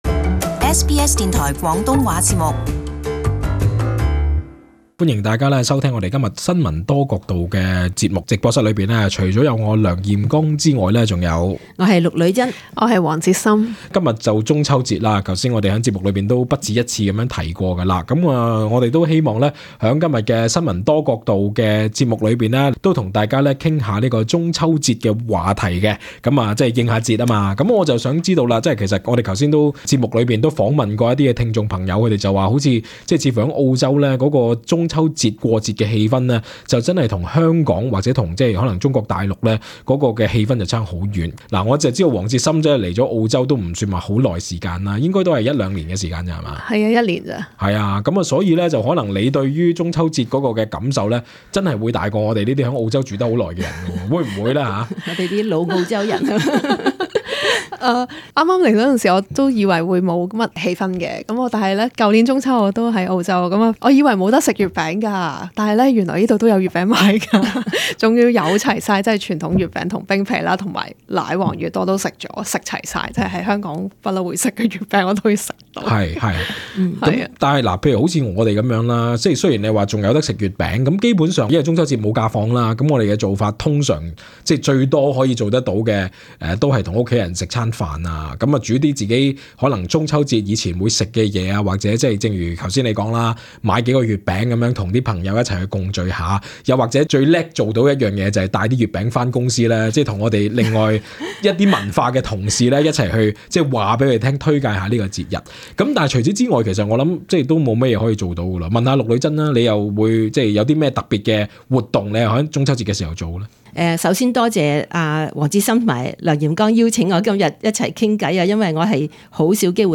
三人亦會就最近澳洲不繼出現生果受惡意破壞的消息，探討如何可以安心食用士多啤梨。